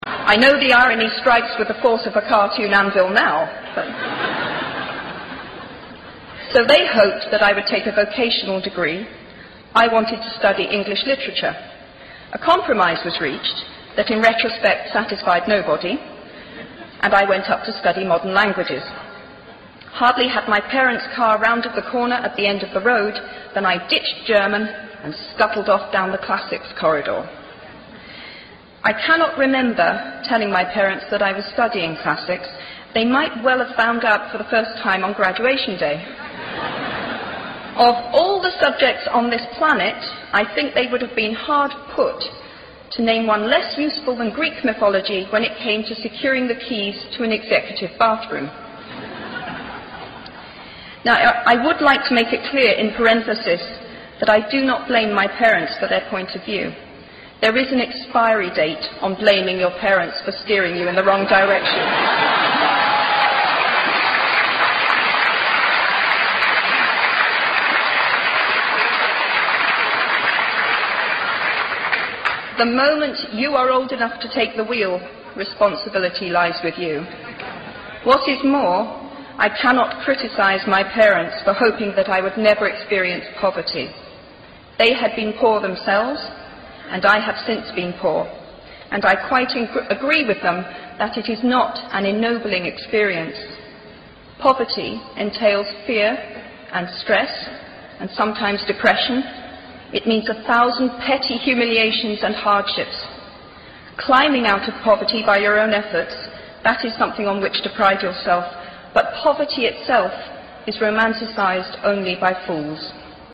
偶像励志英语演讲21:失败的好处和想象的重要性(3) 听力文件下载—在线英语听力室